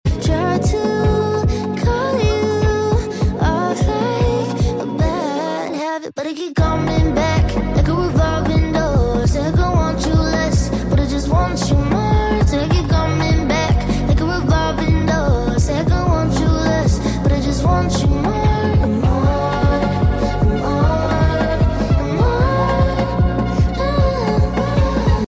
Revolving Door >> Sound Effects Free Download